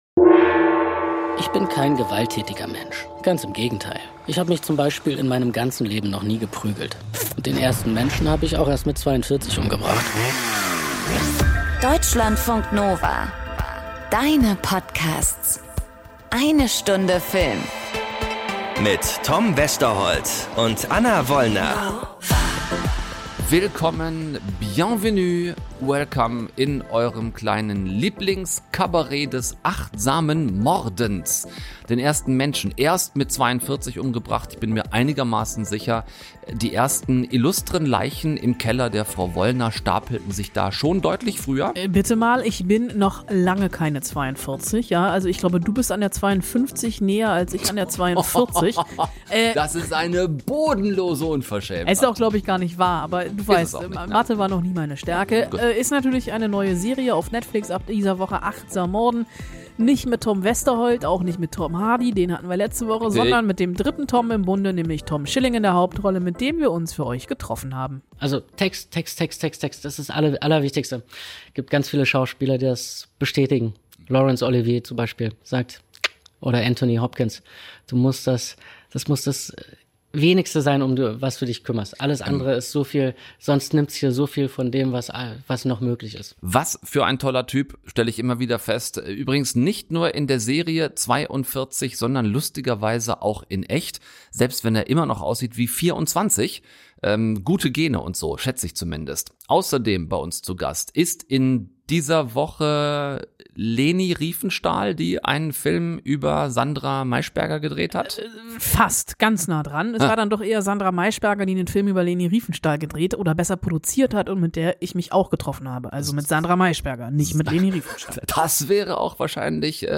Schauspieler Benno Fürmann erzählt im Interview über den frühen Tod seiner Eltern, einen schweren Unfall als Jugendlicher und seine Liebe zur Natur.